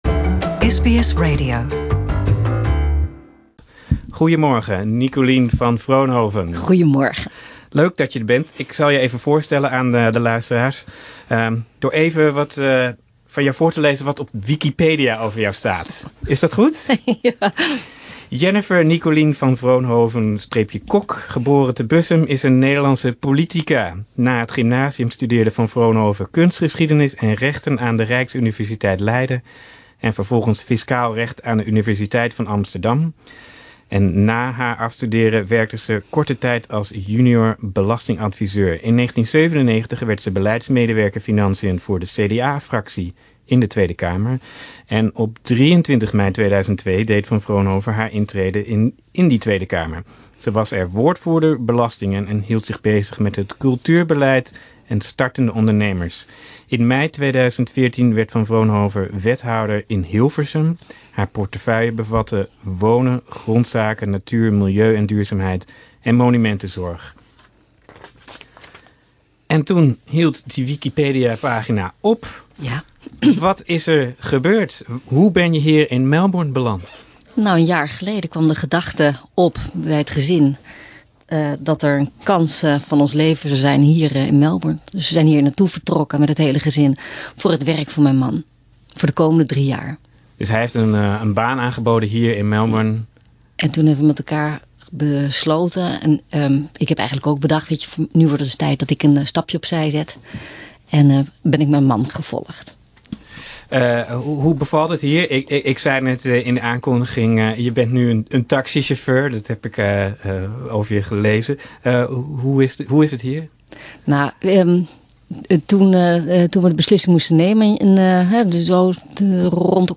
Listen to the interview in Dutch.